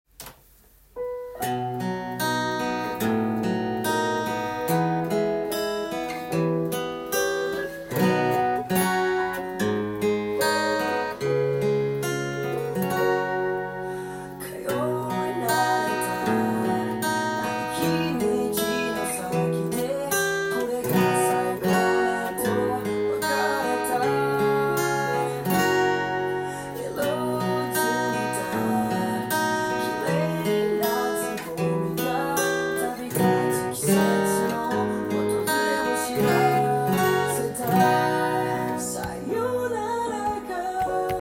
音源にあわせて譜面通り弾いてみました
卒業の季節に合いそうなウルっと来る曲です。
4カポで弾けるtab譜にしてみました。